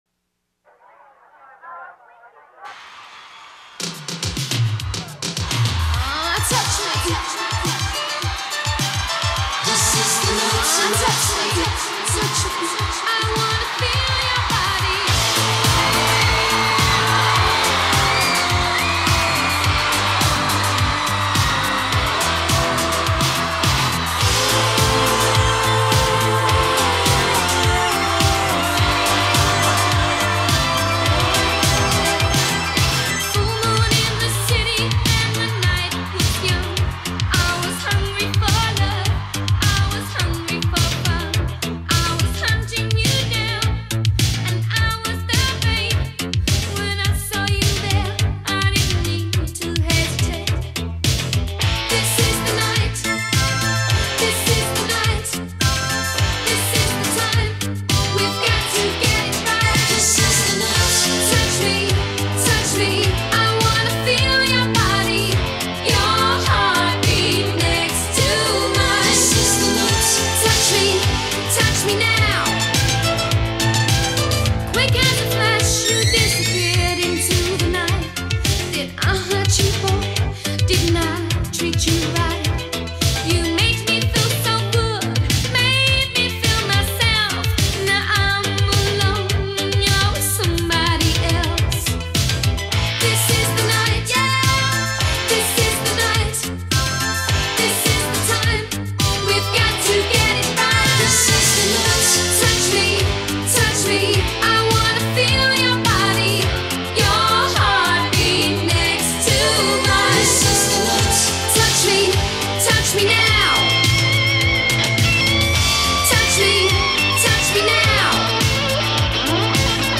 Duke kaluar në muzikën pop pas tre vjetësh si vajzë